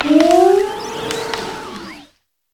Cri de Virevorreur dans Pokémon Écarlate et Violet.